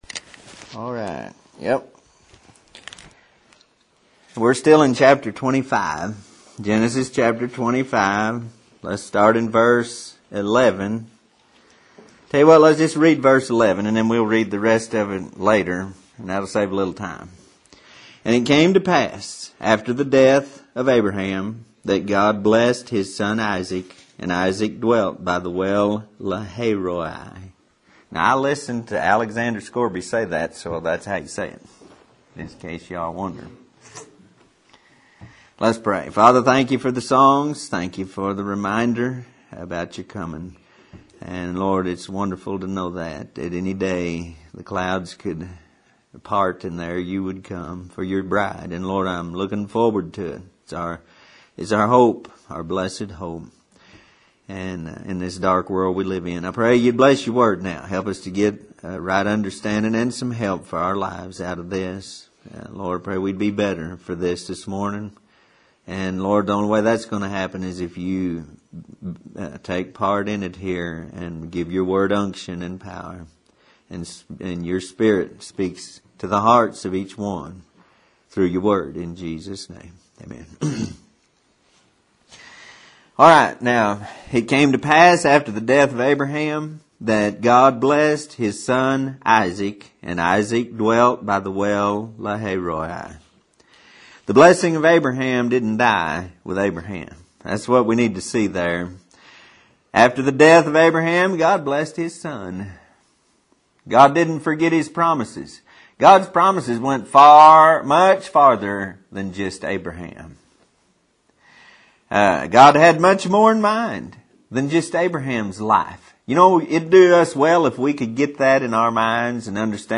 Sermon Series on Abraham of the Old Testament